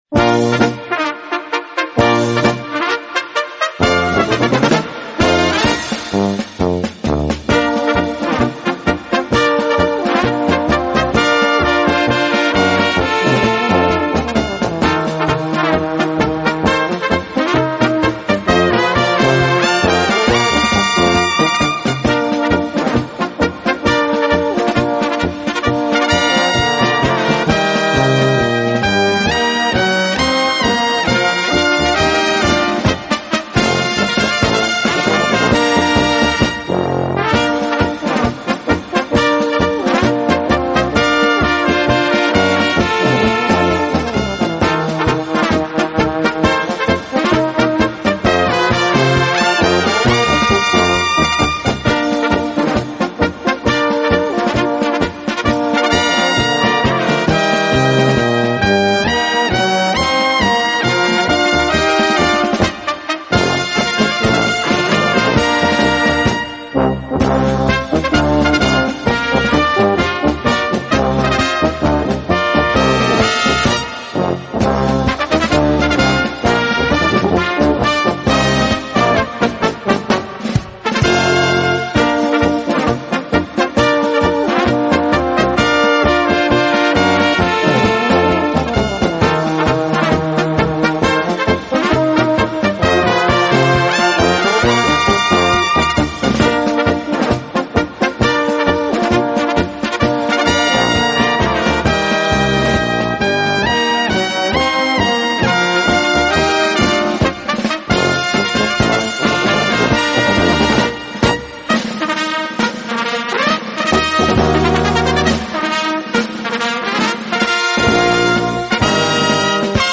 Gattung: Konzertmarsch für kleine Besetzung
Besetzung: Kleine Blasmusik-Besetzung
Einzigartiger Konzertmarsch